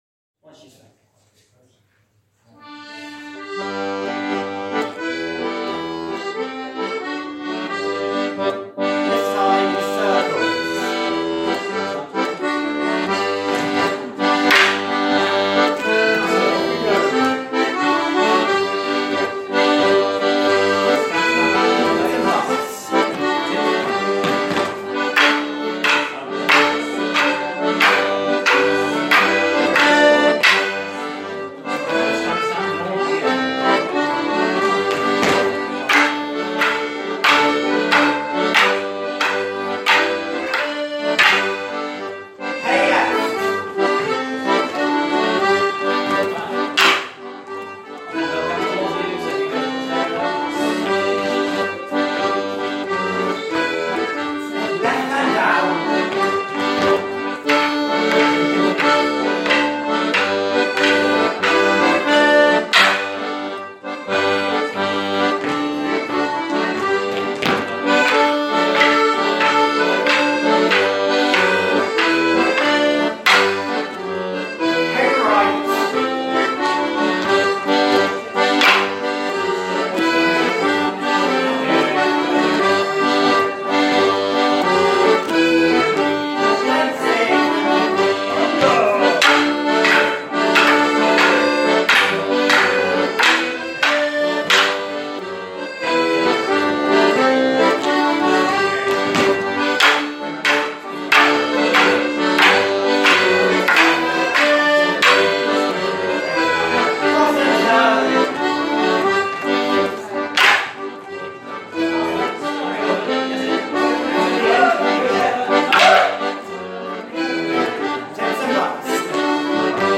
• Melodeons (D/G)
• Fiddles
At the beginning of 2019 we embarked on a project of recording our practices so that the tunes can be used for personal music practice purposes.